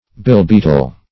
Search Result for " billbeetle" : The Collaborative International Dictionary of English v.0.48: Billbeetle \Bill`bee"tle\, or Billbug \Bill"bug`\, n. (Zool.)
billbeetle.mp3